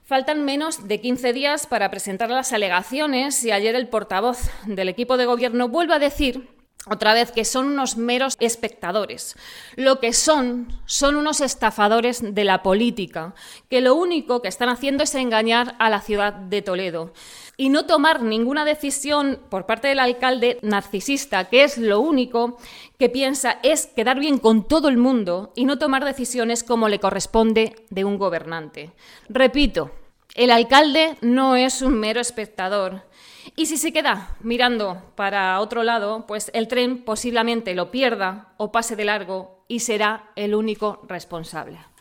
En rueda de prensa, la concejala Laura Villacañas, ha denunciado que Carlos Velázquez debe elegir entre ser un mero espectador o asumir su responsabilidad como alcalde porque, le guste o no, forma parte de la ecuación para elegir el mejor trazado para Toledo.